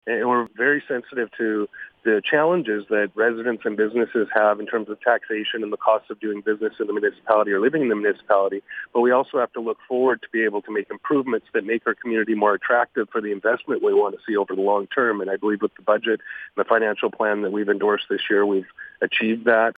Mayor Aaron Stone says last year council settled on a significant increase in taxes to address some infrastructure issues but he says this year they managed to keep increases to a minimum… ..